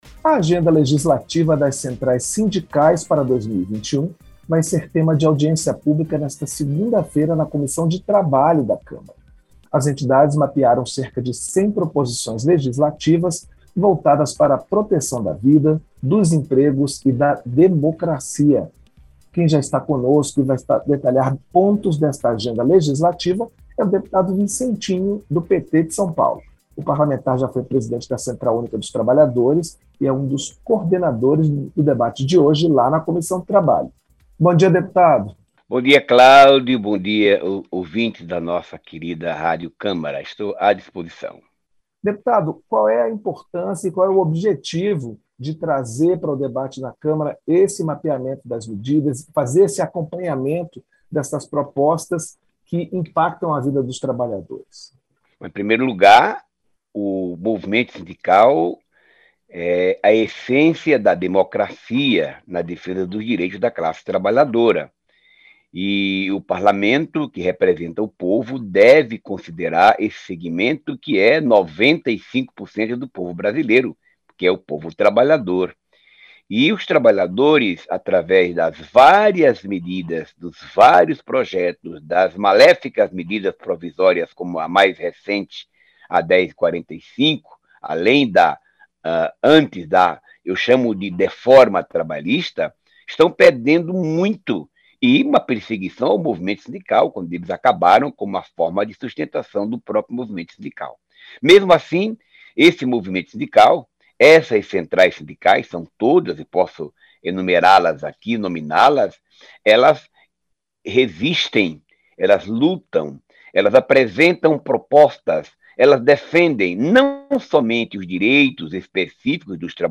Entrevista - Dep. Dep. Vicentinho (PT-SP)